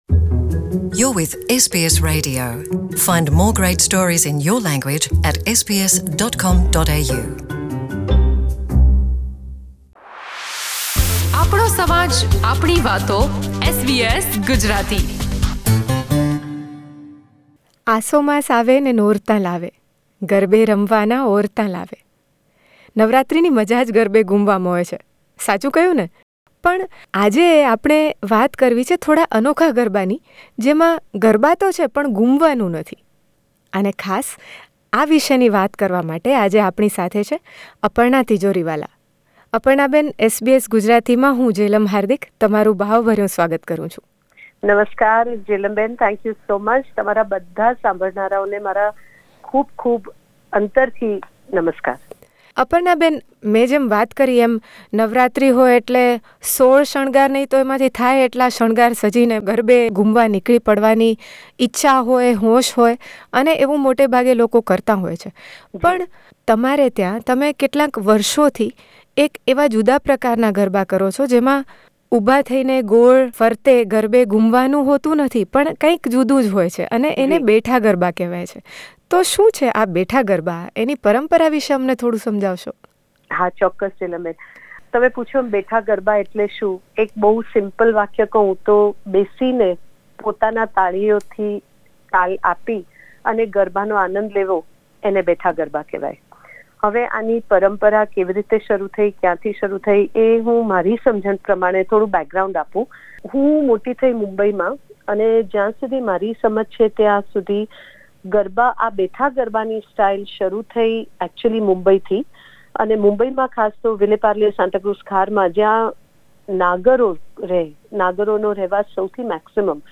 She talks about the history of unique tradition of singing Garbas- praising different forms of the Divine Goddess during the Navaratri celebration. While singing some melodious lines, she weaves a touching episode of her life as the inspiration for the Betha Garba.